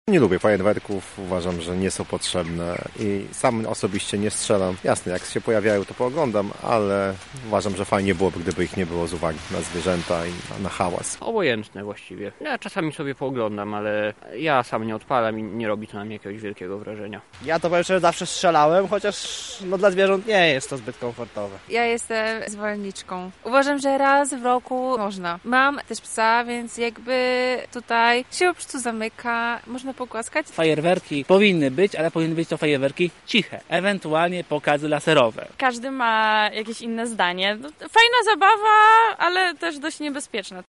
SONDA: Co lublinianie myślą o fajerwerkach?